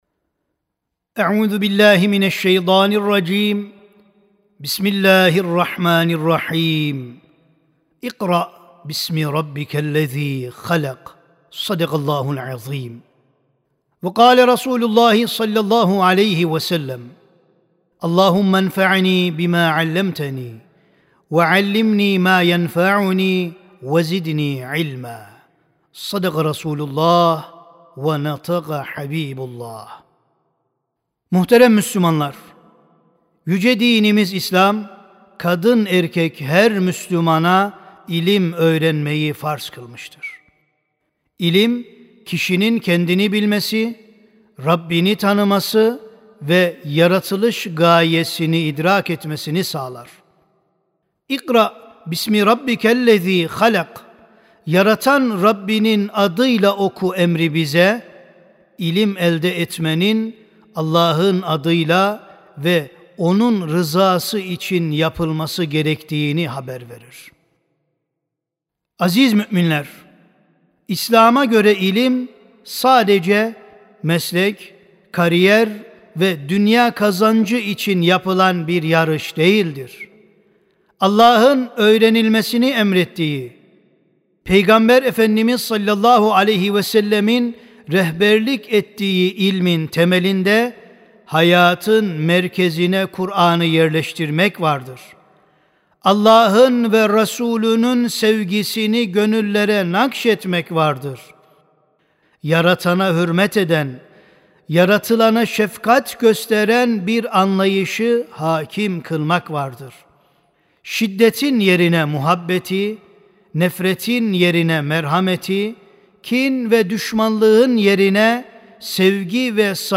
05 Eylül 2025 Tarihli Cuma Hutbesi
Sesli Hutbe (İlim Hakkı Bilmektir).mp3